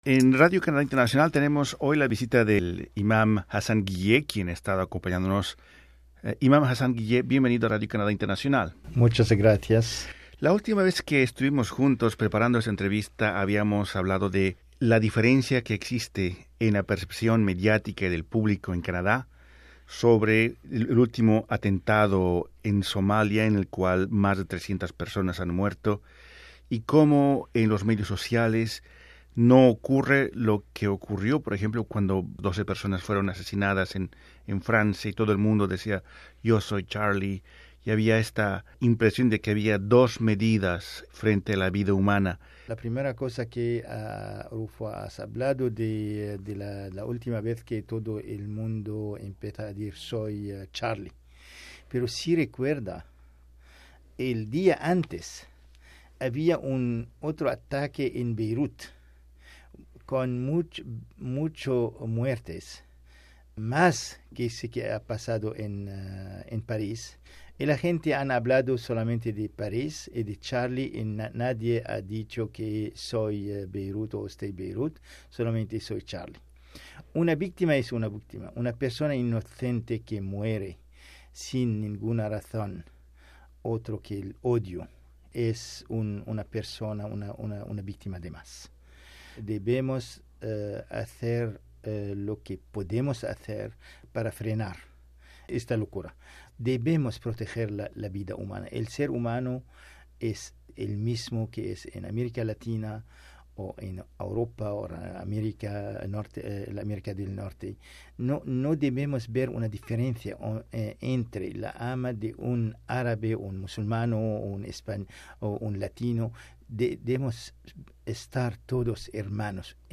Entrevistado por Radio Canadá Internacional